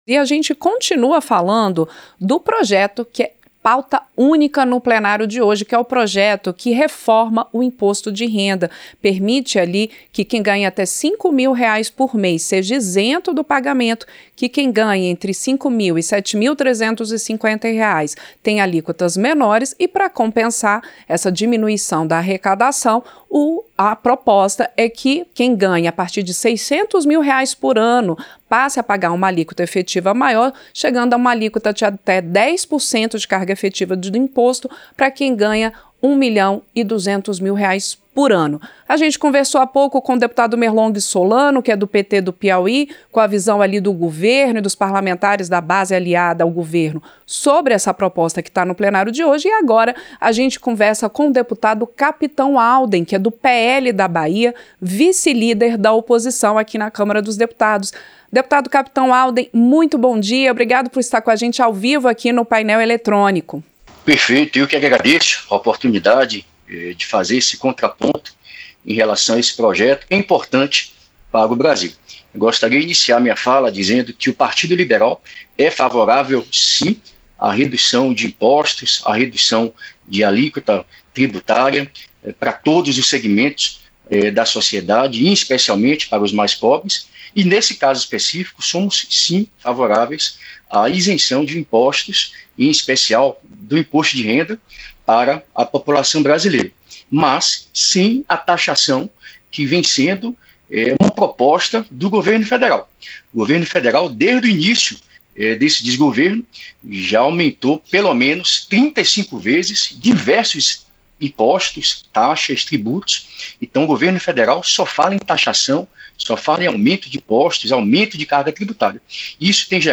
Entrevista - Dep. Capitão Alden (PL-BA)